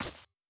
Impact on Grass.wav